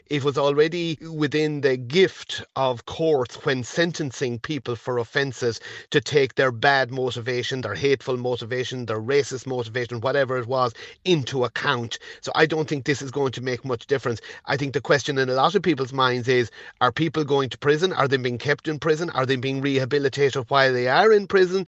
Independent Senator Ronan Mullen says the new legislation won’t change much: